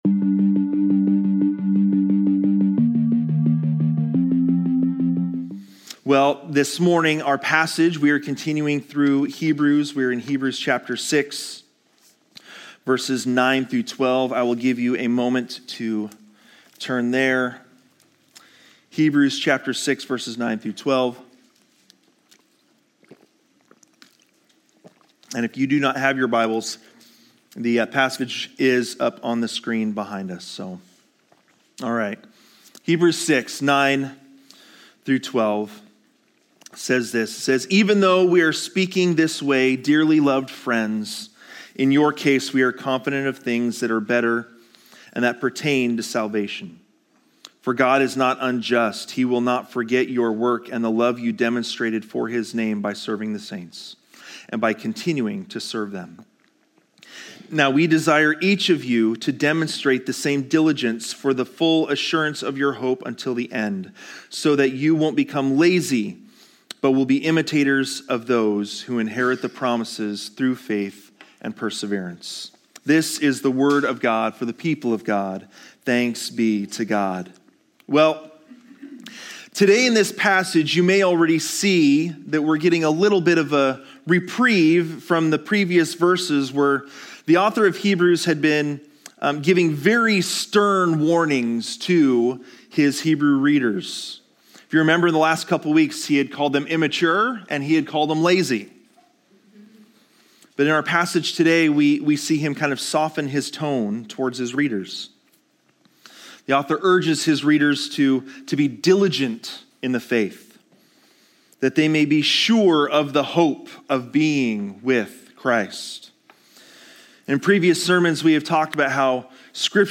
Sermons | Living Word Community Church